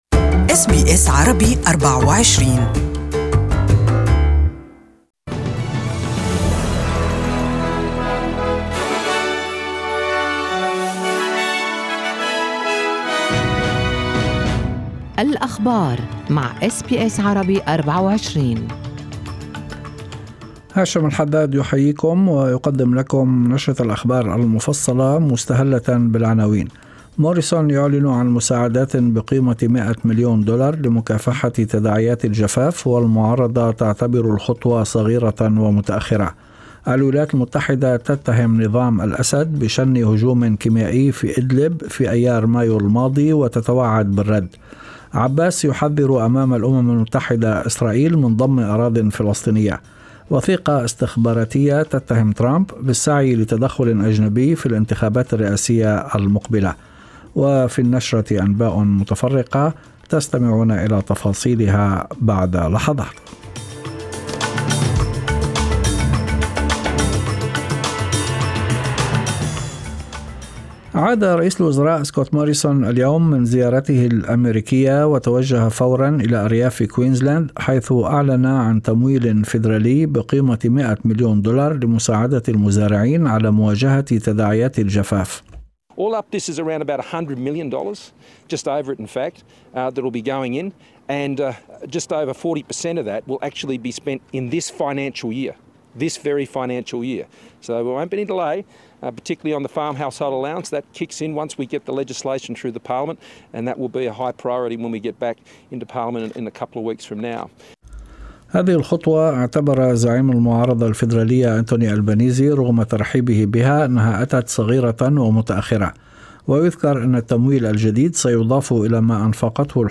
Evening News: 'Taking action': PM flies to Queensland to roll out $100 million drought fund